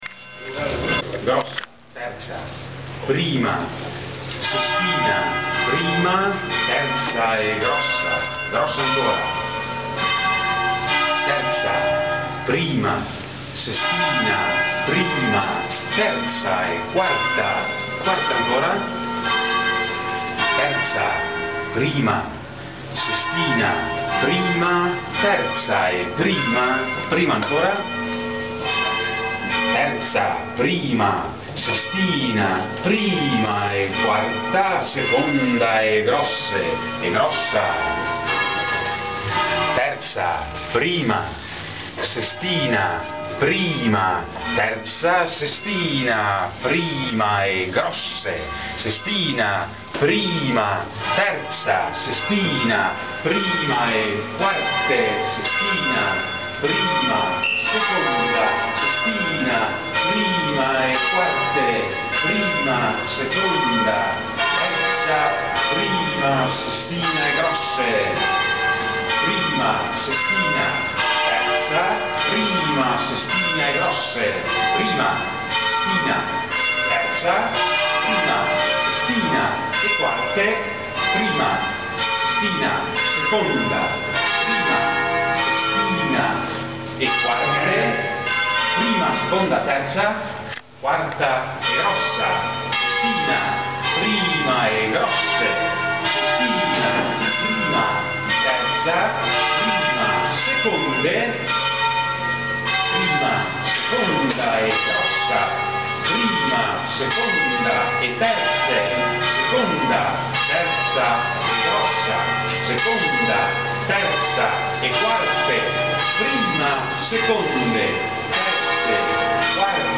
Il campanile e la voce delle campane
L'attuale concerto, intonato in Mi bemolle, è pertanto costituito da sei campane disposte a sistema di movimento manuale e montate su incastellatura metallica.
campane-3.mp3